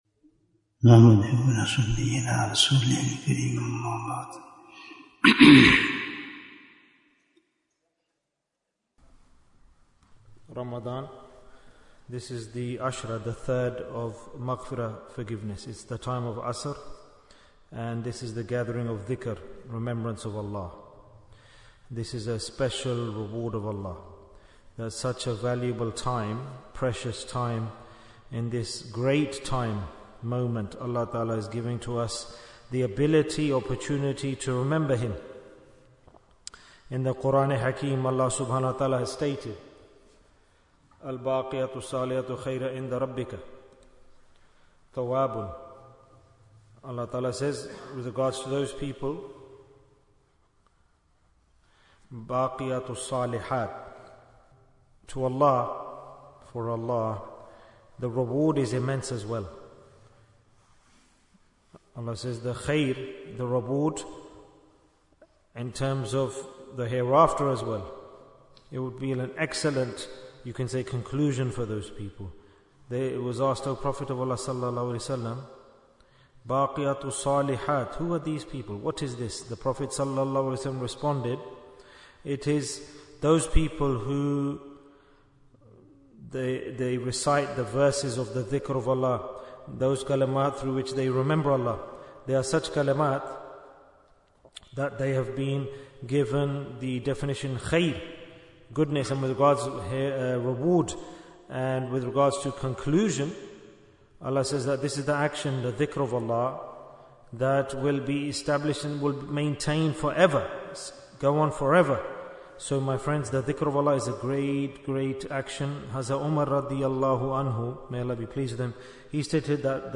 Jewels of Ramadhan 2025 - Episode 21 Bayan, 17 minutes17th March, 2025